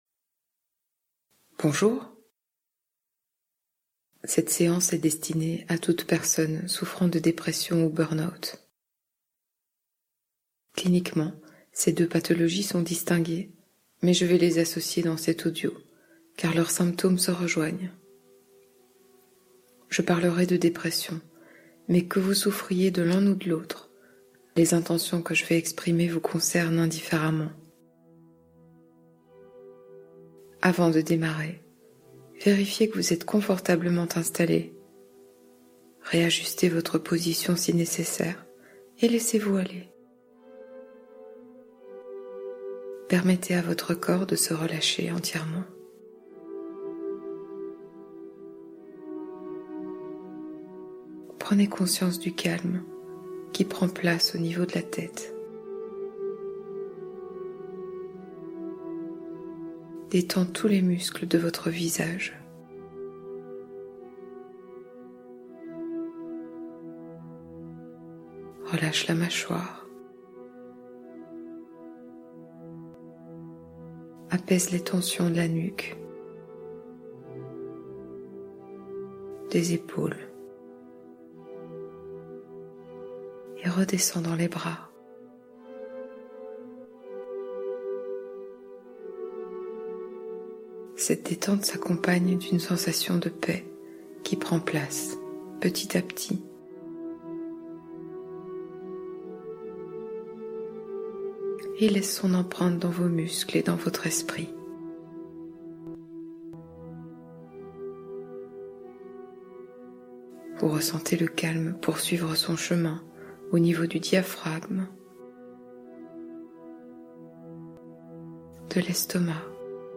Soutien Émotionnel : Hypnose pour accompagner le burn-out